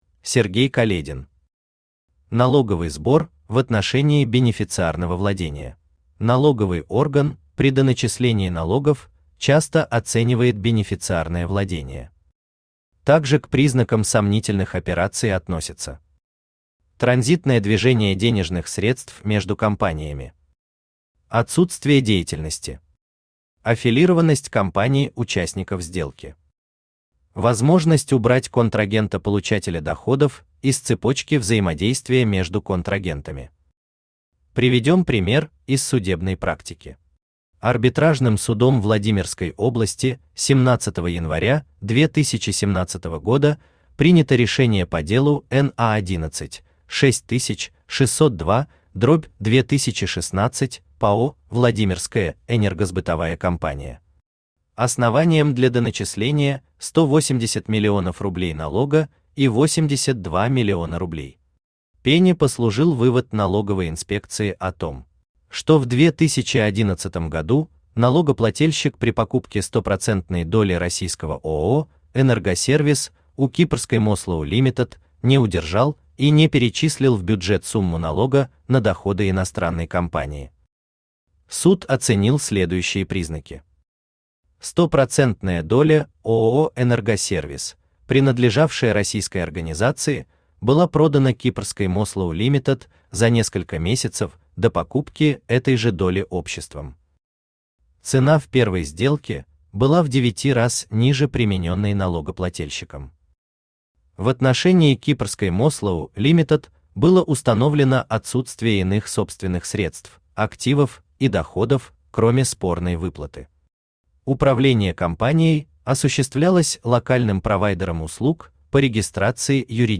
Аудиокнига Налоговый сбор в отношении бенефициарного владения | Библиотека аудиокниг
Aудиокнига Налоговый сбор в отношении бенефициарного владения Автор Сергей Каледин Читает аудиокнигу Авточтец ЛитРес.